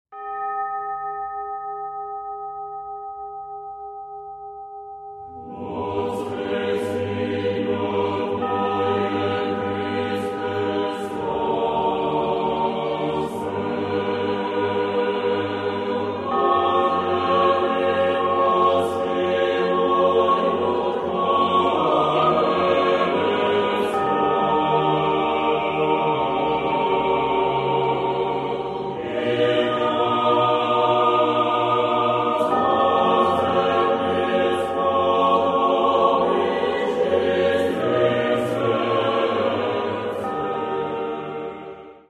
Каталог -> Классическая -> Хоровое искусство